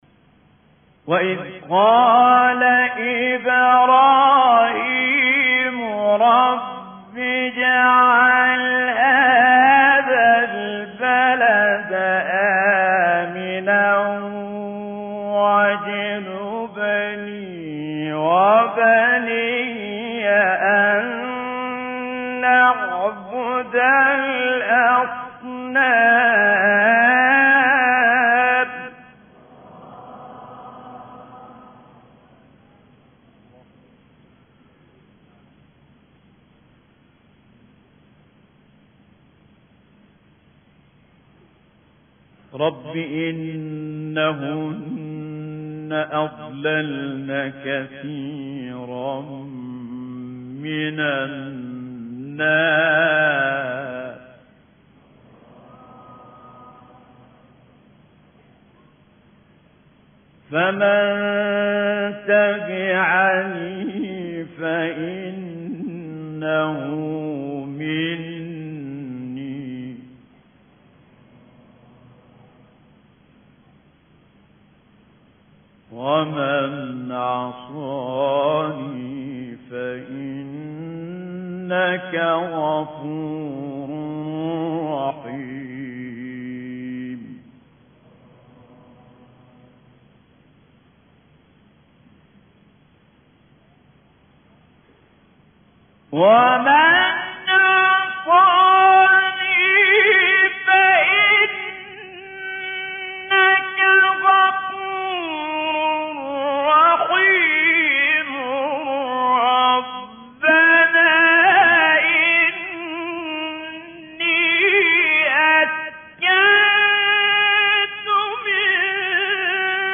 آیه 35-41 سوره ابراهیم استاد متولی عبدالعال | نغمات قرآن | دانلود تلاوت قرآن